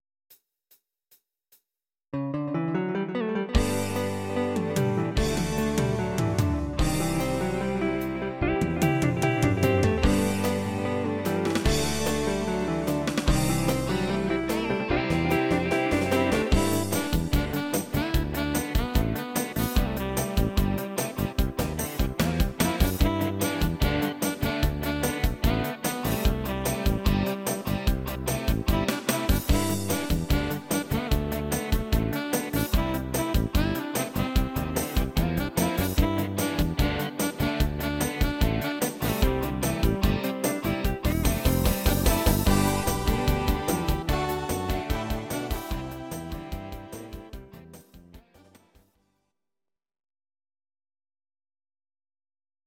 Audio Recordings based on Midi-files
Our Suggestions, Pop, Rock, 1970s